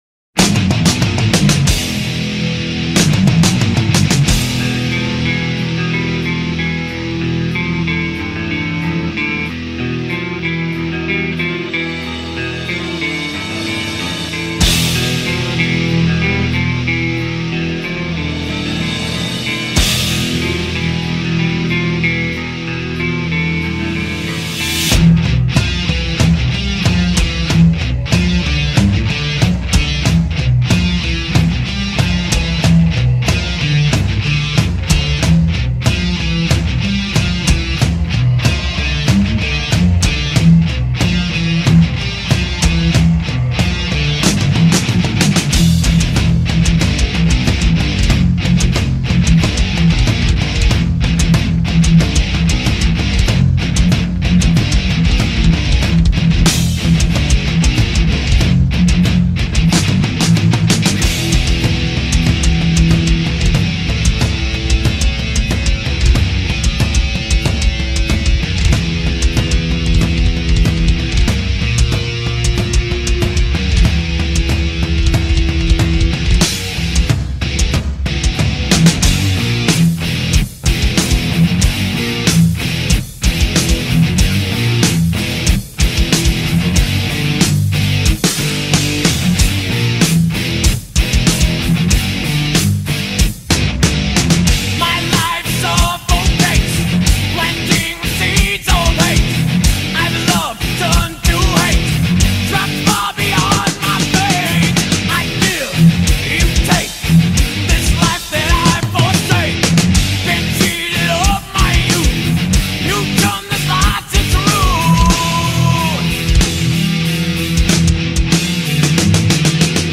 Genero: Metal
Calidad: Stereo (Exelente)(Remasterizado)